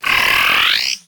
Cri de Khélocrok dans Pokémon HOME.